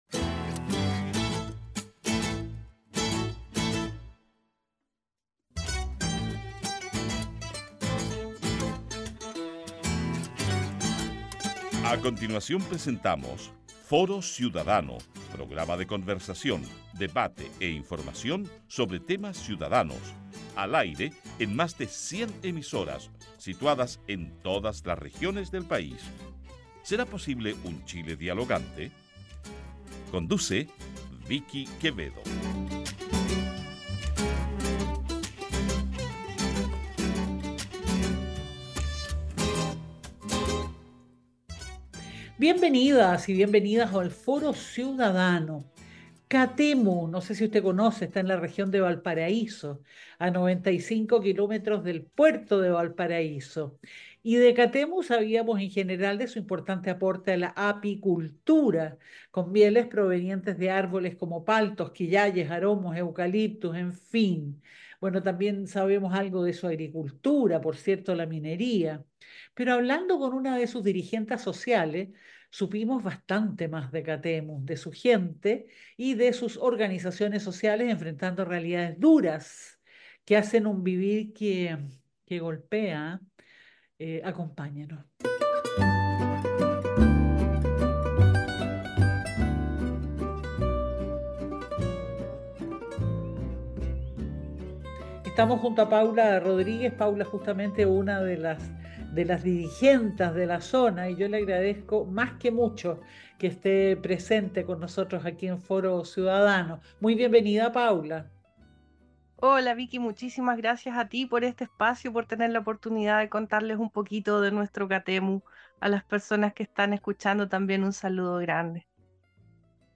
Conversamos con una dirigenta social, supimos de su gente y de sus organizaciones ciudadanas, enfrentando realidades duras que hacen un vivir que golpea.